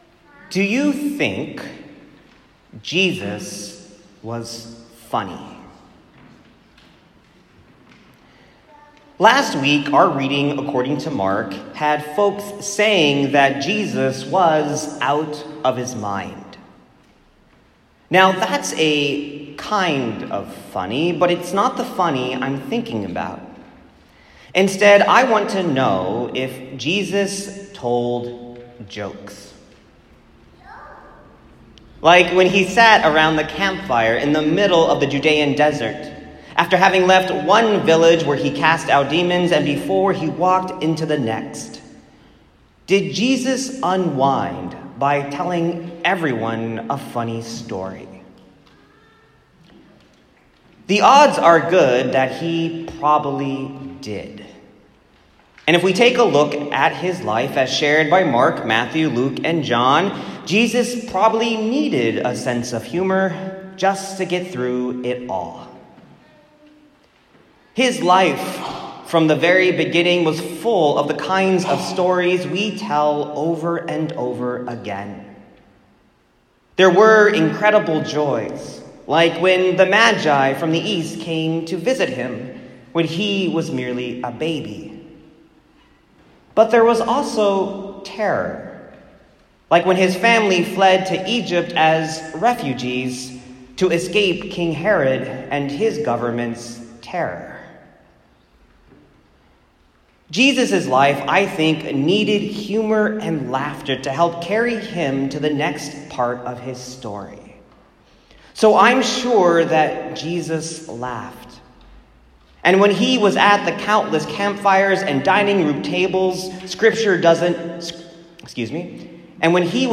My sermon from 4th Sunday after Pentecost (June 17, 2018) on Mark 4:26-34.